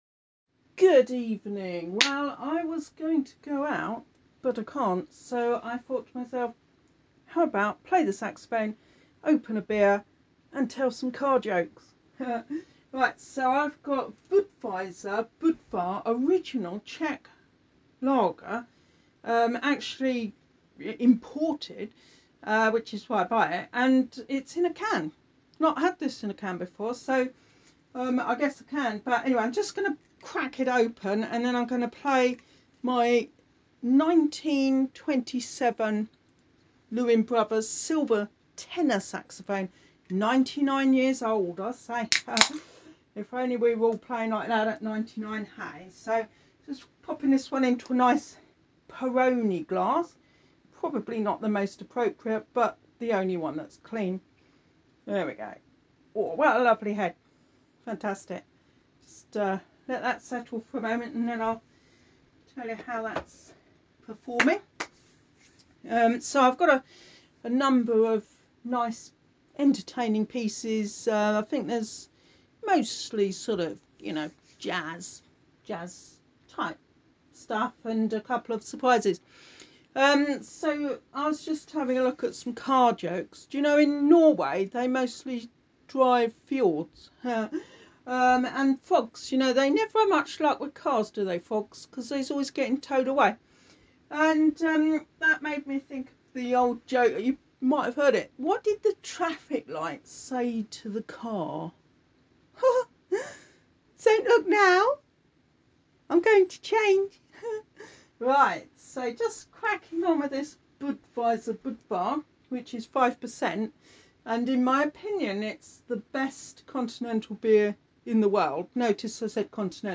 Tenor sax performance with car jokes